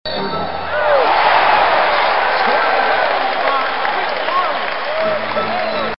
Звук ликующих зрителей на баскетбольном матче